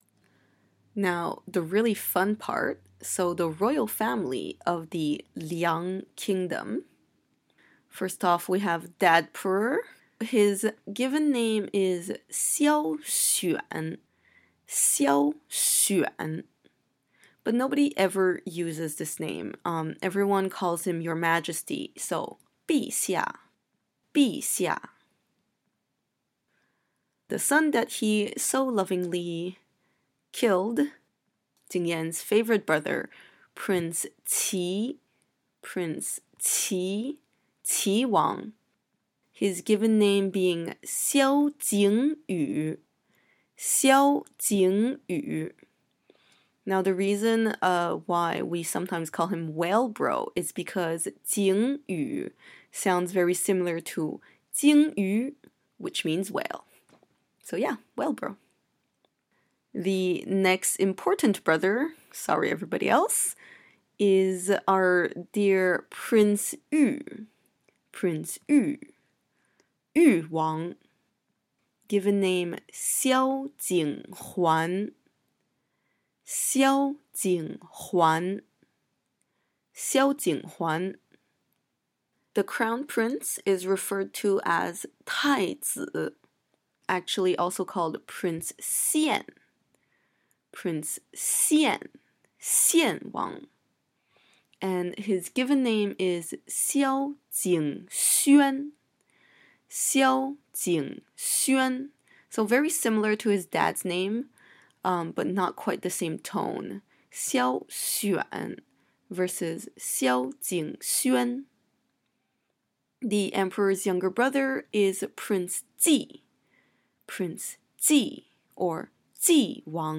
Nirvana in Fire pronunciation guide
I believe my accent is fairly Standard but feel free to disagree.